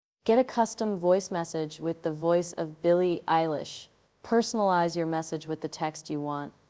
Get a custom voice message with the voice of Billie Eilish.
The audio message is generated by artificial intelligence and should not be used to create fake news and commit crimes.